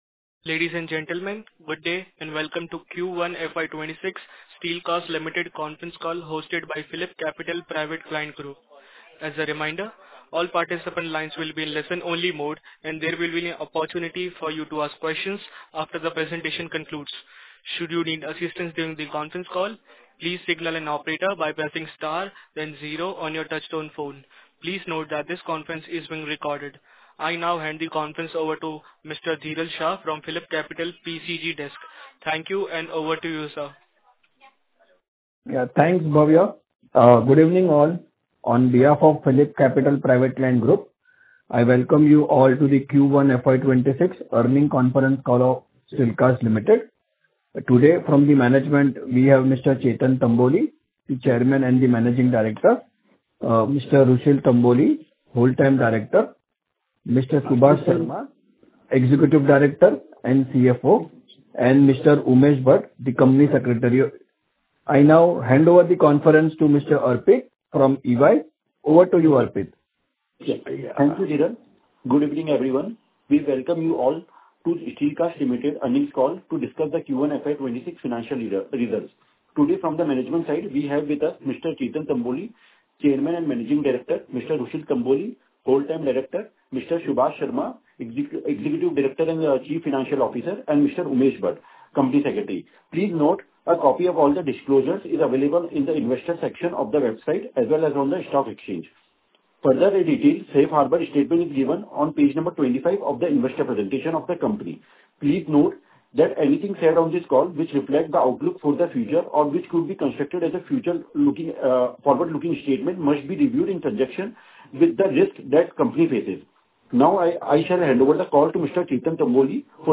Earnings Call Recording
Q1FY26_Earning_Call_Audio_Recording.MP3